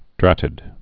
(drătĭd)